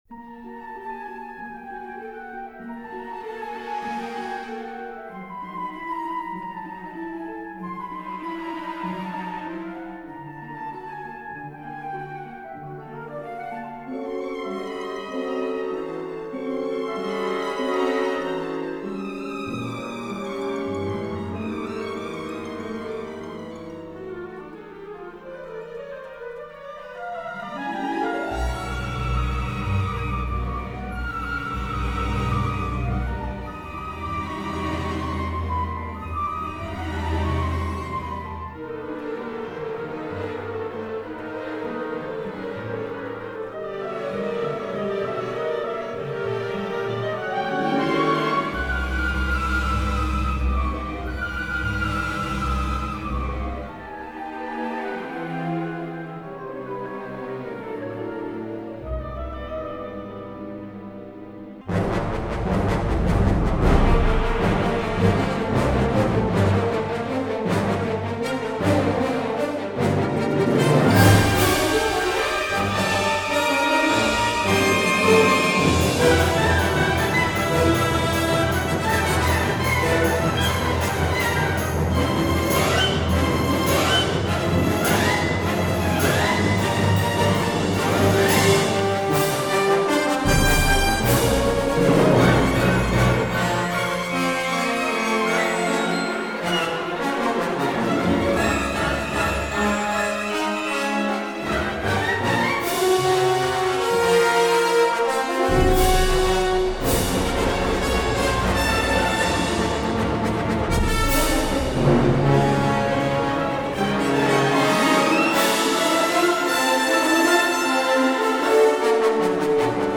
Full Orchestra